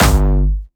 Jumpstyle Kick 5